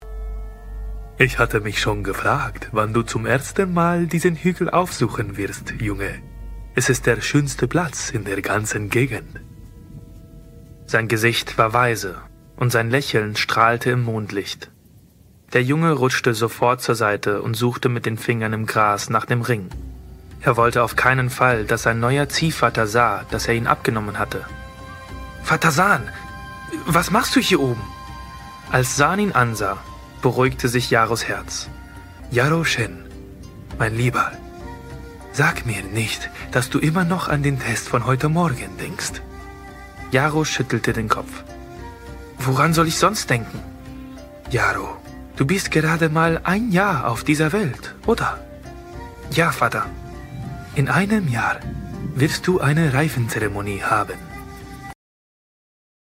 sehr variabel, hell, fein, zart, markant
Mittel minus (25-45)
Sprachprobe Erzähler Deutsch 2
Audio Drama (Hörspiel), Audiobook (Hörbuch)